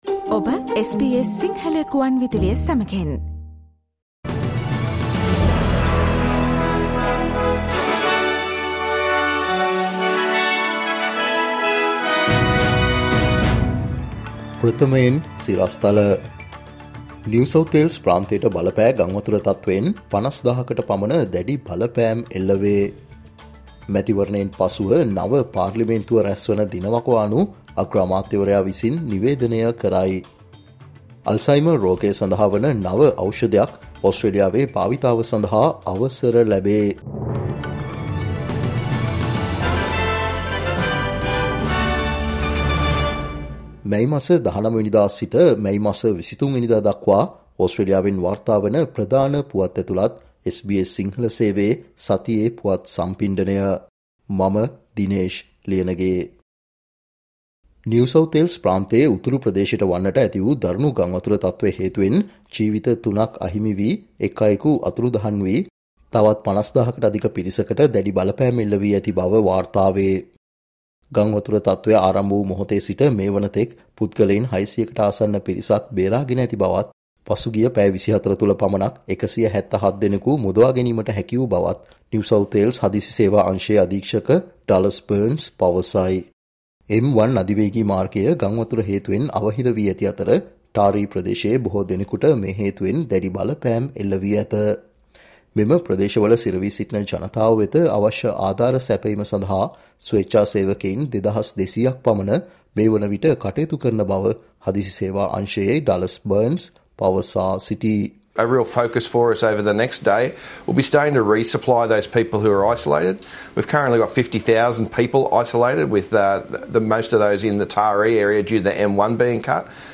'මේ සතියේ ඔස්ට්‍රේලියාව': SBS සිංහල ගෙන එන සතියේ ඕස්ට්‍රේලියානු පුවත් සම්පිණ්ඩනය, මැයි 19 - මැයි 23